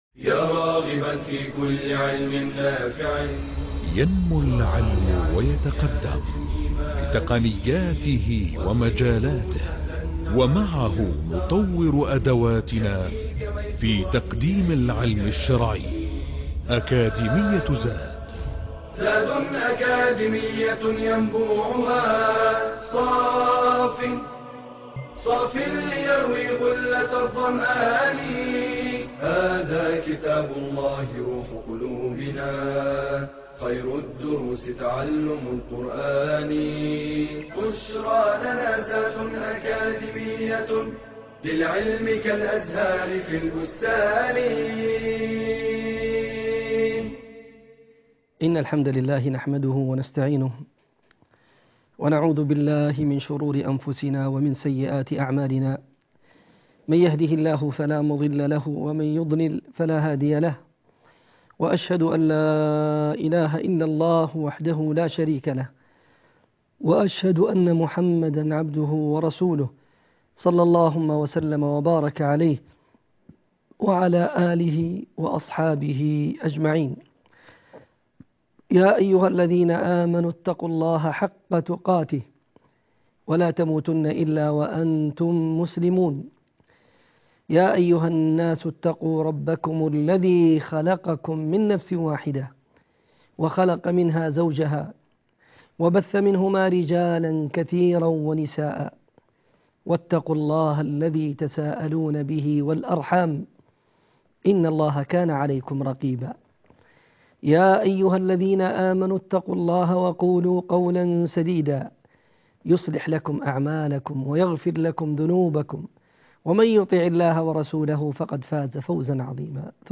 المحاضرة الثامنة عشر- سورة البلد